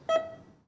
Hearbeat Monitor Sound.wav